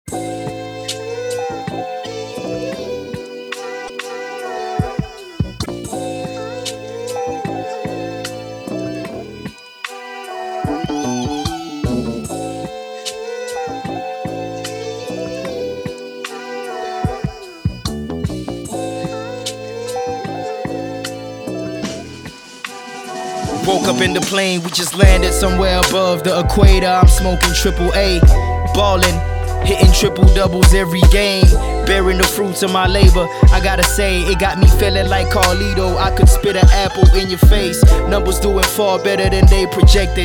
combines the best of Hip-hop and Rap